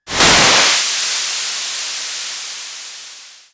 GasReleasing07.wav